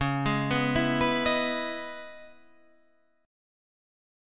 Dbm9 Chord
Listen to Dbm9 strummed